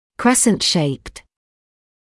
[‘kresnt ʃeɪpt][‘крэснт шэйпт]имеющий форму полумесяца